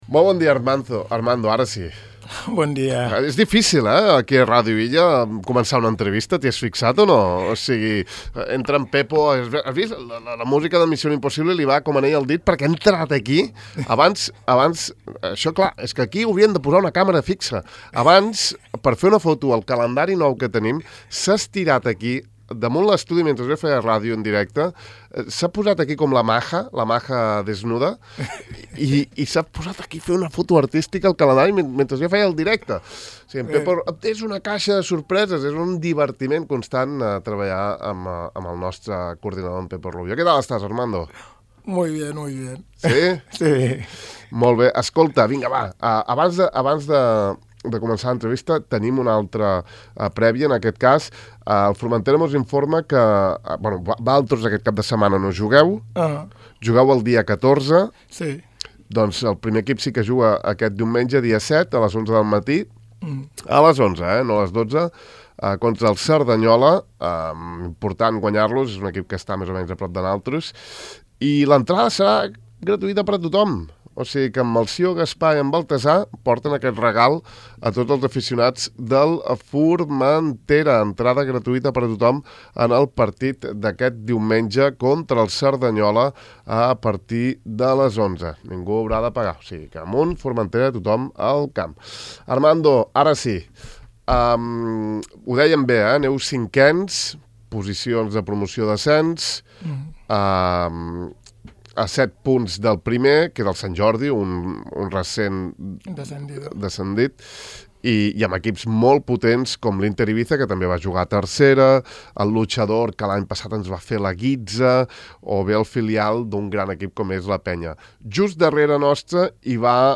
Aquest matí hem entrevistat